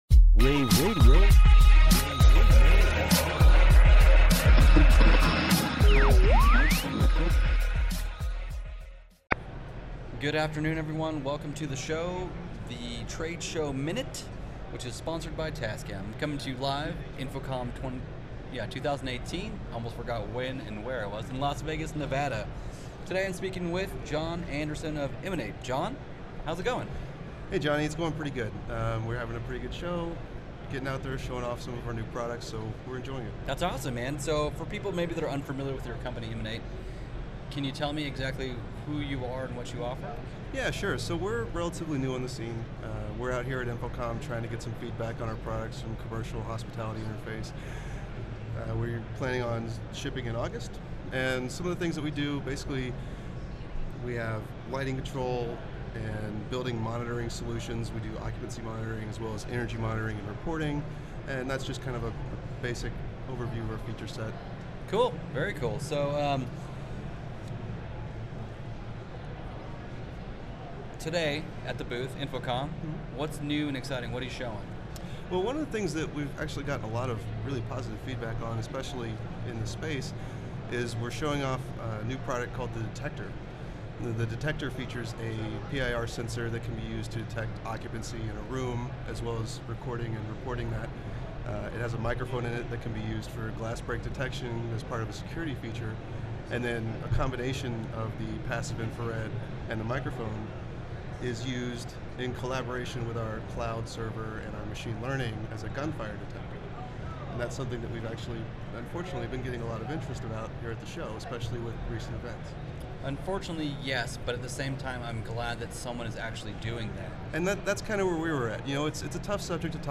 InfoComm Day2_showmin-234.mp3